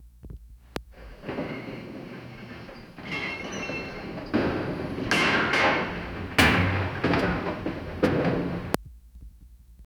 Create the rhythmic clatter of train tracks, wind whipping past, metal creaking, punches landing, bodies slamming into walls, and distant horn blasts. Add the occasional screech of brakes 0:10 Lions roar is accompanied by a high pitched screech form a giant monster.
create-the-rhythmic-clatt-wdjgwxmf.wav